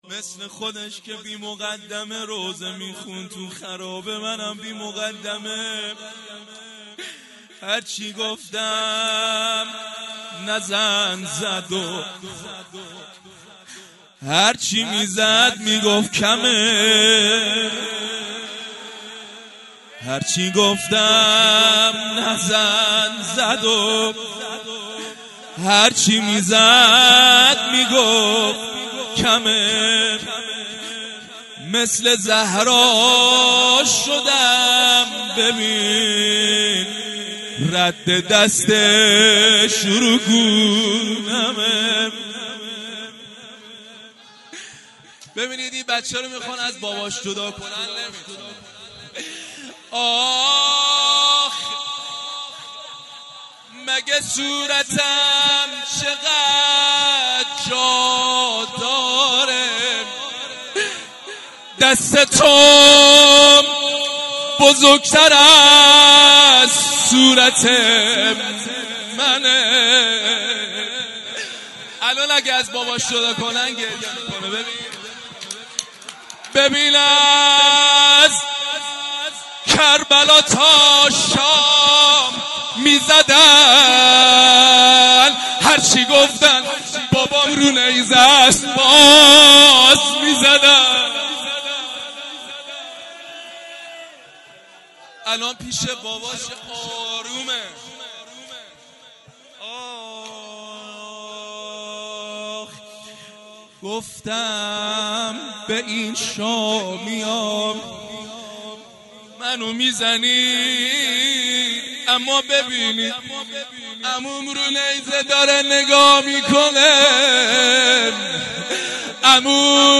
خیمه گاه - هیئت زواراباالمهدی(ع) بابلسر